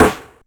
Rimshot3.aif